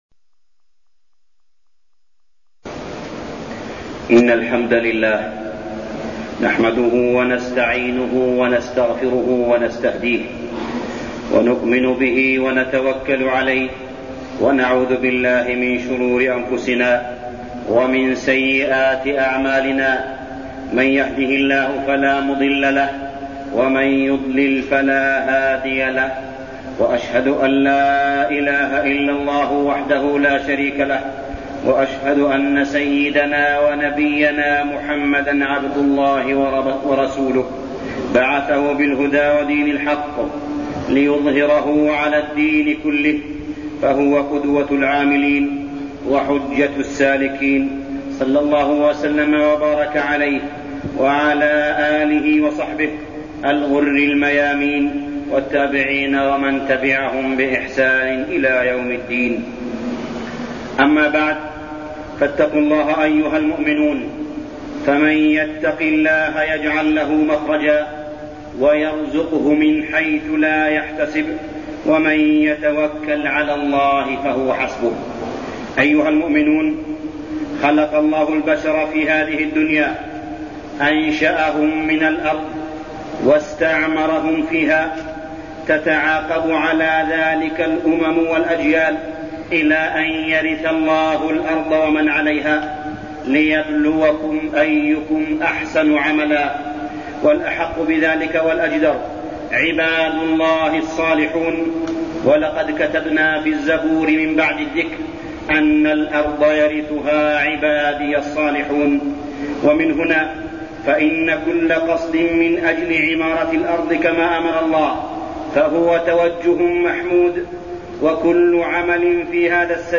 تاريخ النشر ٨ صفر ١٤١٠ هـ المكان: المسجد الحرام الشيخ: معالي الشيخ أ.د. صالح بن عبدالله بن حميد معالي الشيخ أ.د. صالح بن عبدالله بن حميد أسباب القوة والنصر